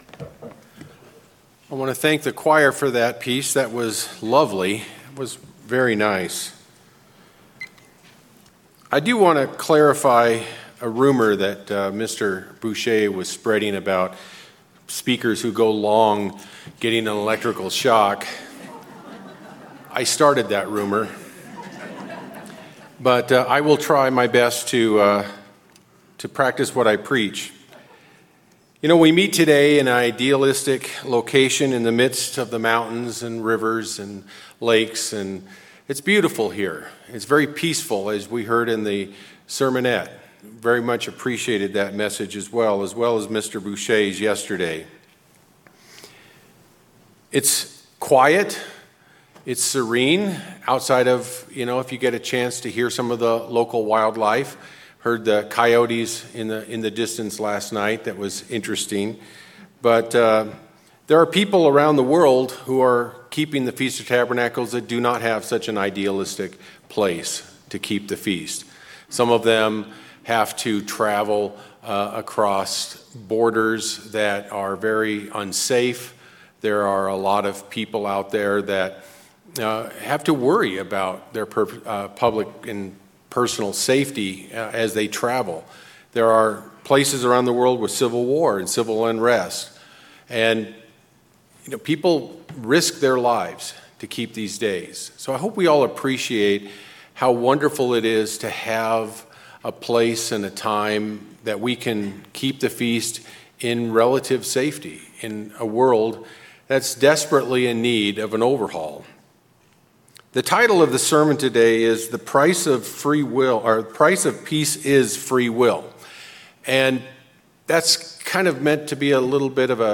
Sermons
Given in Klamath Falls, Oregon